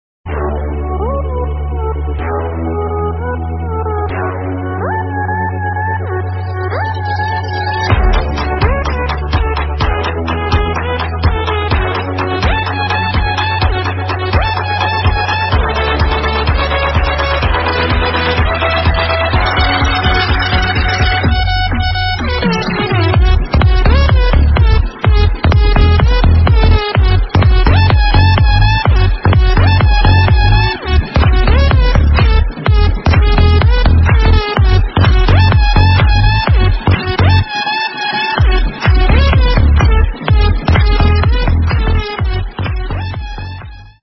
end-point-bg-music.mp3